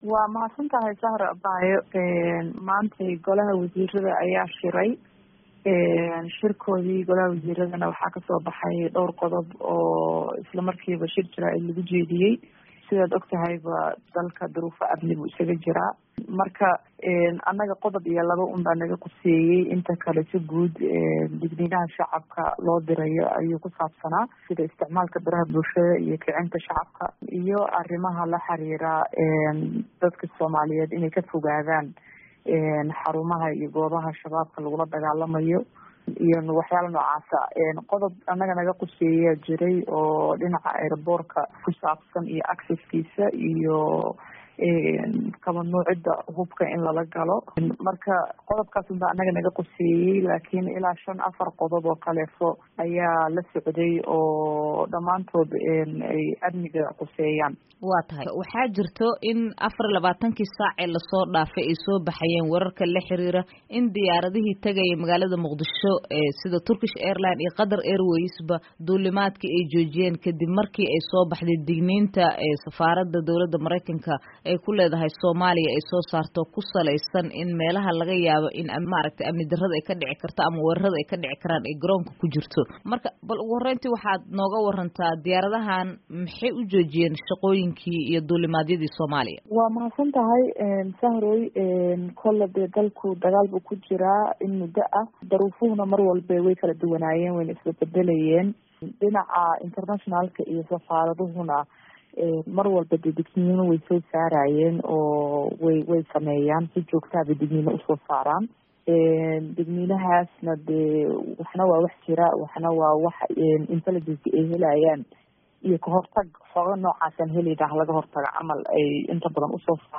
Wasiirka Gaadiidka iyo Duulista Hawada ee Xukuumadda Federaalka Soomaaliya, Fardowsa Cismaan Cigaal oo wareysi siisay Idaacadda VOA, ayaa sheegtay in wax khatar ah aysan dalka ka jirin, isla markaana ay helaan xogta ammaanka dalka iyadoo dhameystiran.